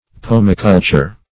Search Result for " pomiculture" : The Collaborative International Dictionary of English v.0.48: Pomiculture \Po"mi*cul`ture\, n. [L. pomum fruit + cultura culture.]